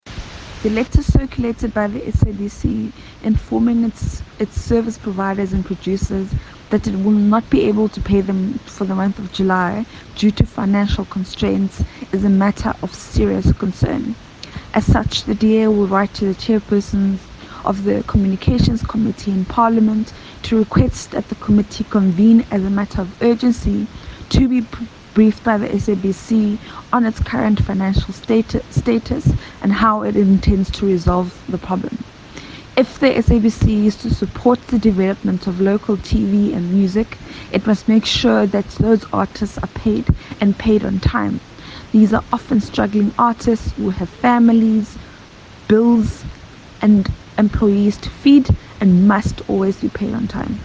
soundbite by the DA Shadow Minister of Communications, Phumzile Van Damme MP.
Phumzile-Van-Damme-SABC-Liquidity-Crisis.mp3